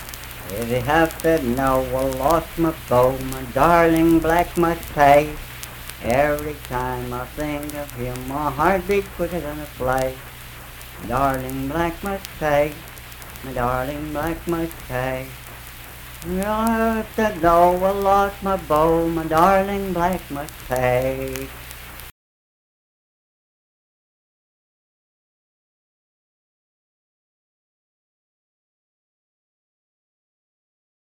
Unaccompanied vocal music performance
Verse-refrain 1(8).
Voice (sung)